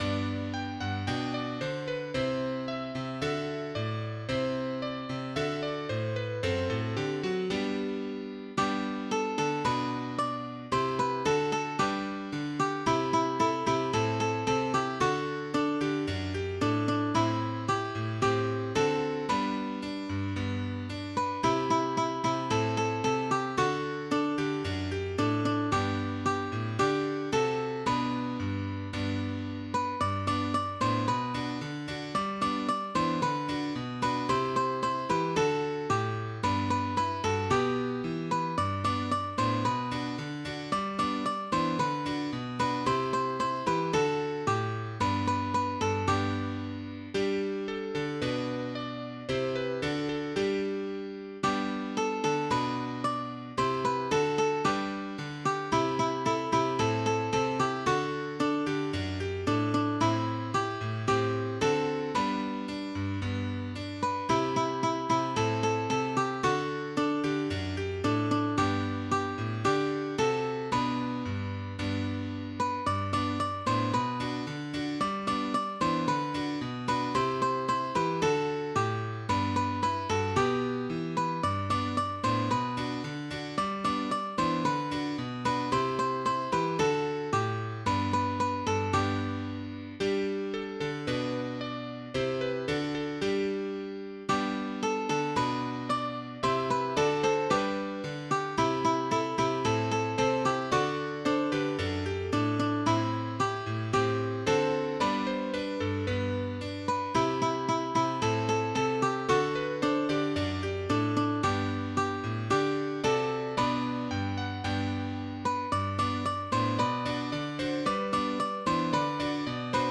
Een vrolijk liedje over verliefd-zijn.